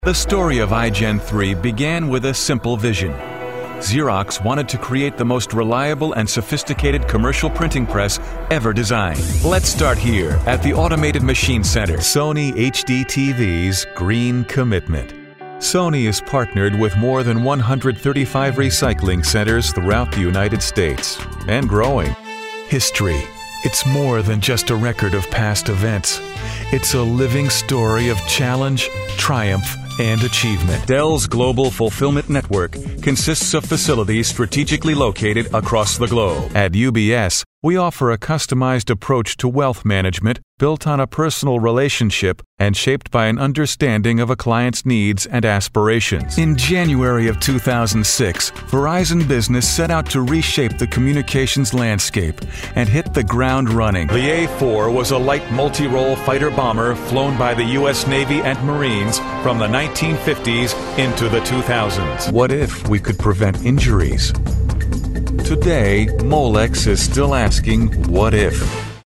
My studio is fully equipped, including ISDN and phone patch for remote recording and direction.
Voice overs, US English, narrations, ISDN, promo, imaging, mid, young, middle, dark, brightly, middle west, mid-Atlantic
Sprechprobe: Industrie (Muttersprache):